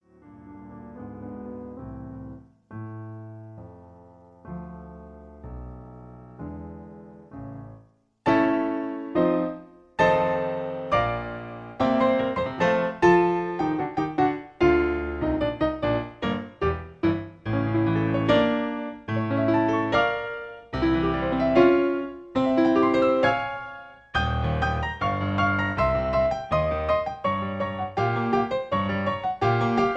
Piano accompaniment track